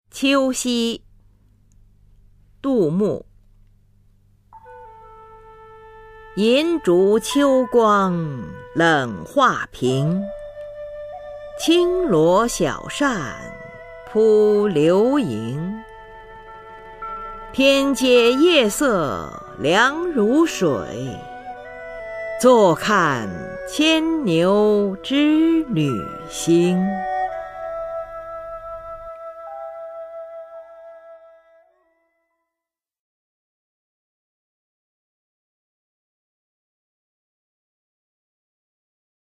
[隋唐诗词诵读]杜牧-秋夕 古诗文诵读